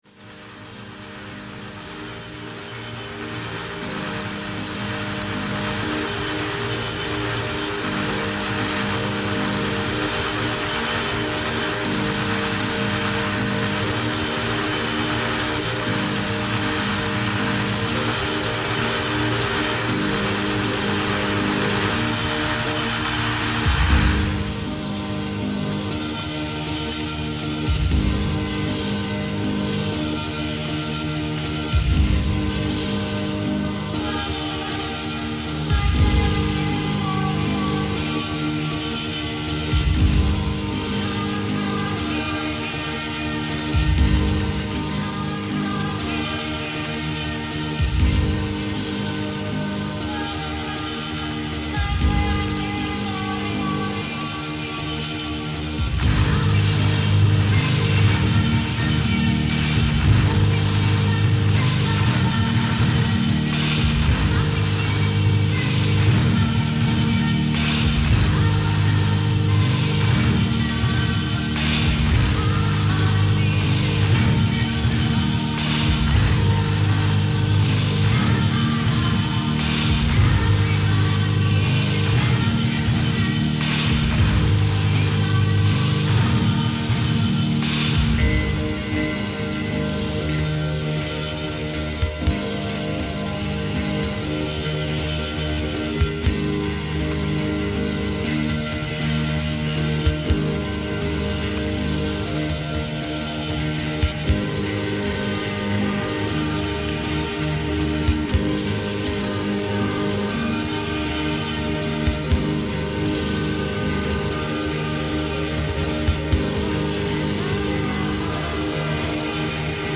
Ambient Avant-Garde Electronic